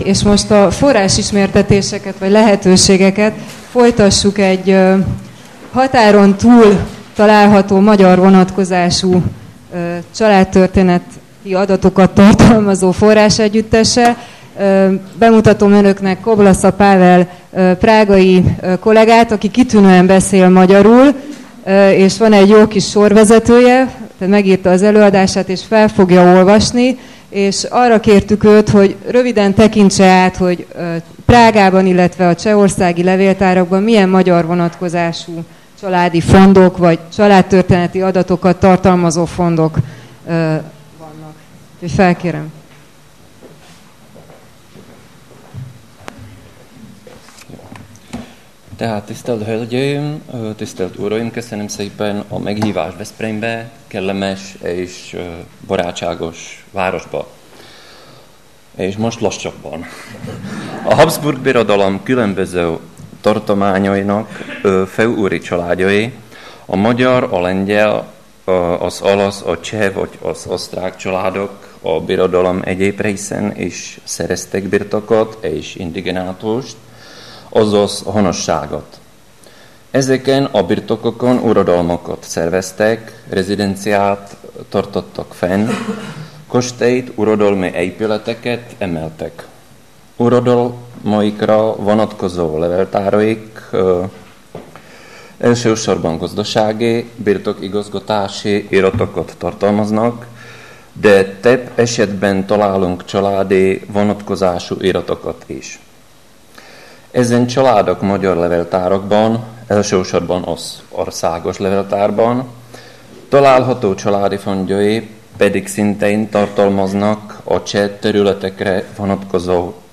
Családtörténeti kutatás az anyakönyveken túl (konferencia)